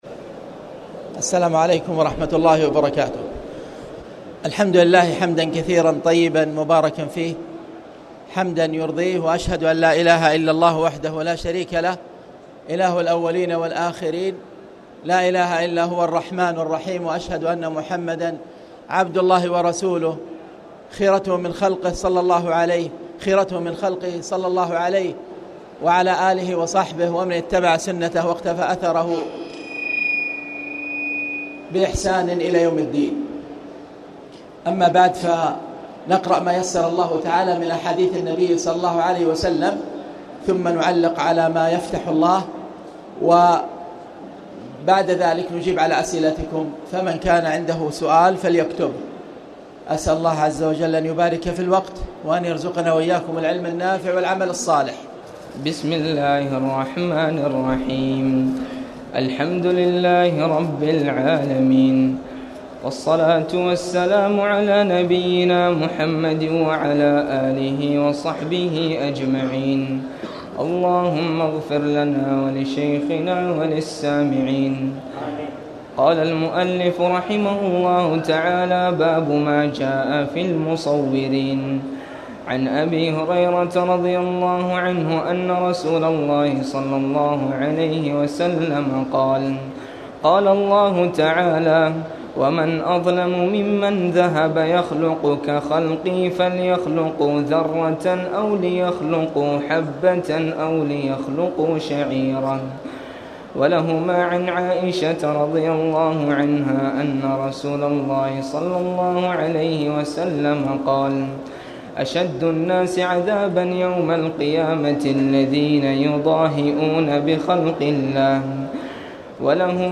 تاريخ النشر ٢٣ رمضان ١٤٣٨ هـ المكان: المسجد الحرام الشيخ